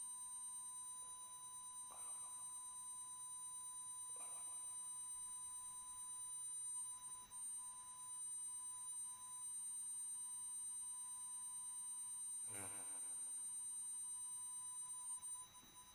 tone design embedded hello
you walk through one of these and you would get the impression that somebody is saying hello to you.
tone-design-embedded-hello.mp3